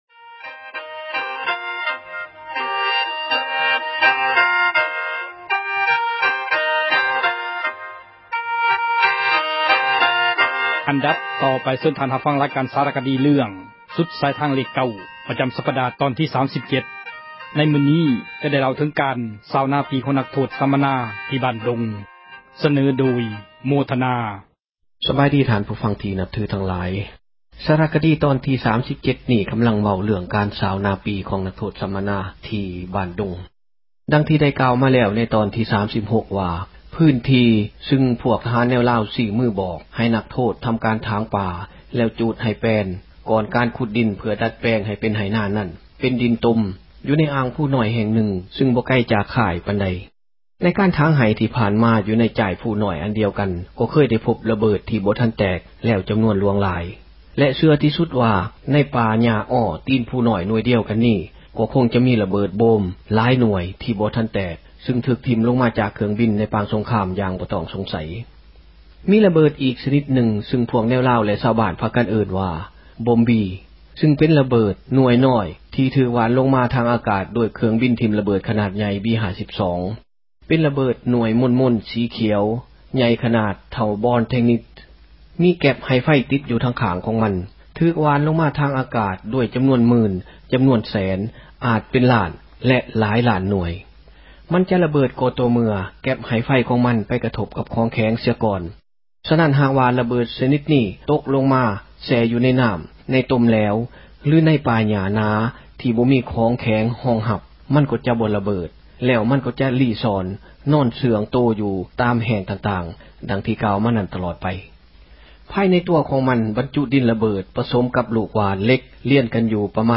ລາຍການ ສາຣະຄະດີ ເຣື້ອງ "ສຸດສາຍທາງເລກ 9" ປະຈໍາສັປດາ ຕອນທີ 37...